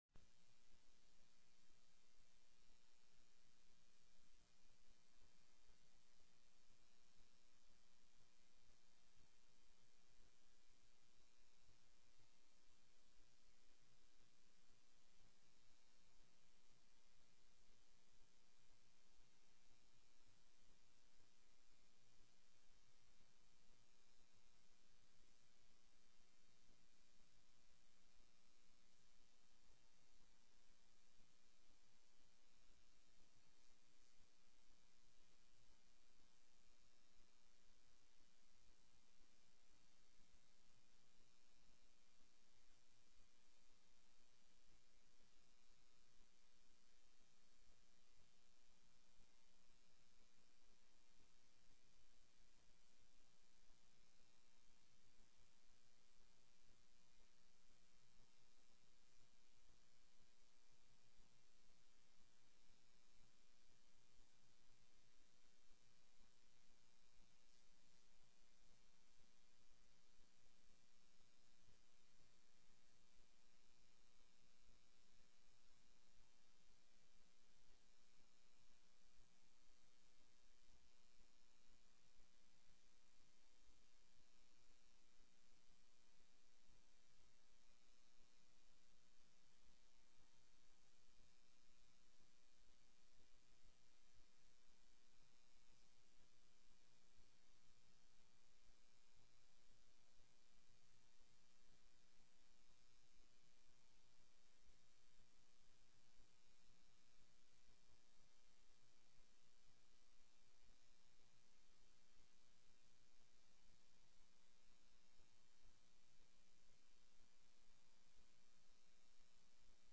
03/20/2012 09:00 AM House FINANCE